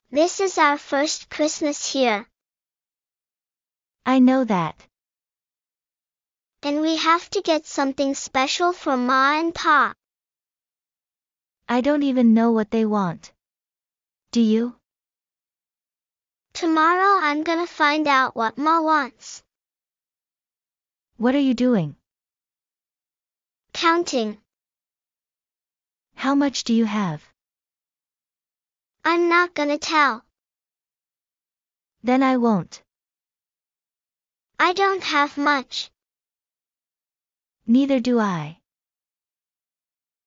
＜ダイアログ音声＞